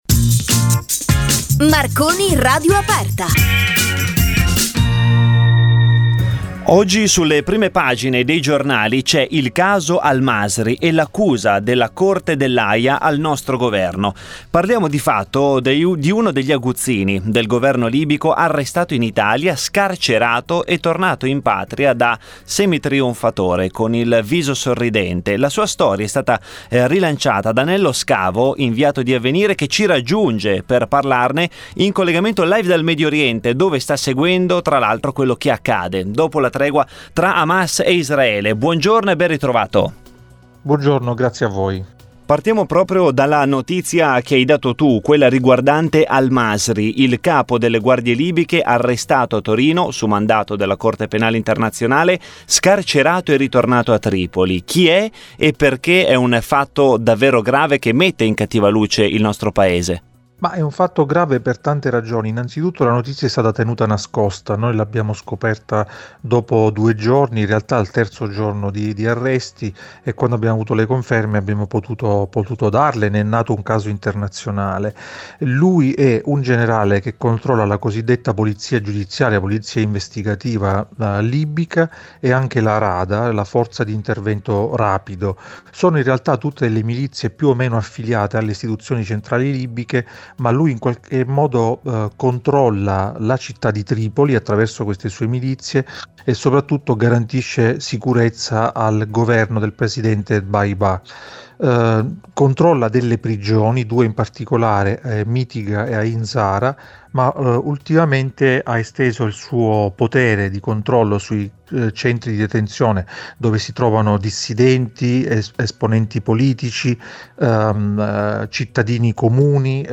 in collegamento con noi dal Medioriente